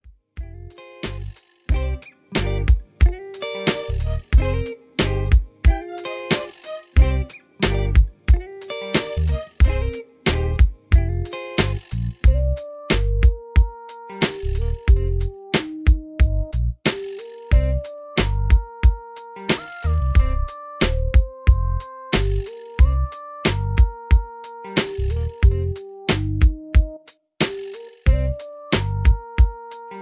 R&B NeoSoul Vibe